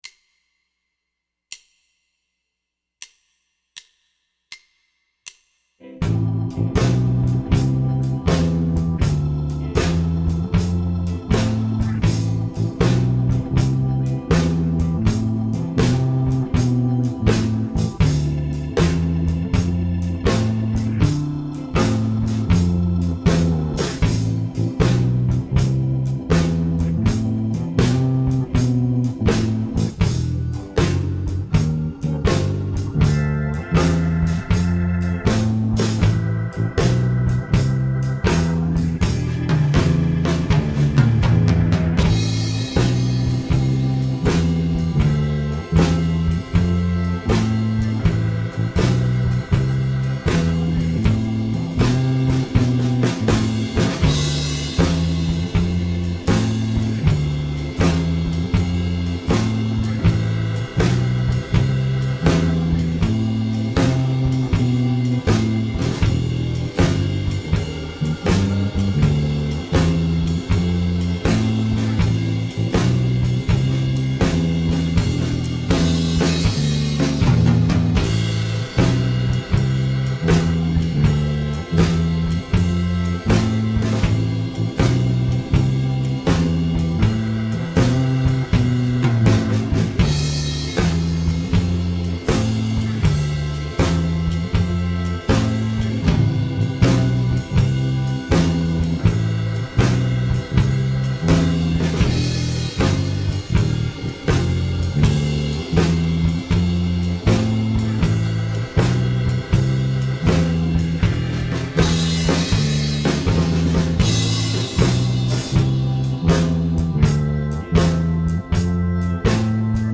Dai il tuo meglio sul Blues minore in 12 battute
Come la volta scorsa suonerò un’improvvisazione pentatonica cercando di fermarmi sempre su una nota dell’accordo.